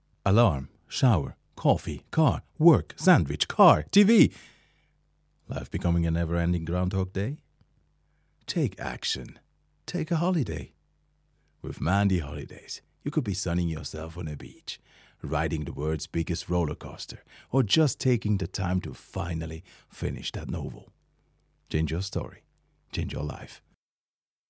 VOICE ENG
Voice: tenor
Voice Character: warm, deep, clear